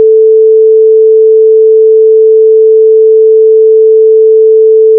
sine440.ogv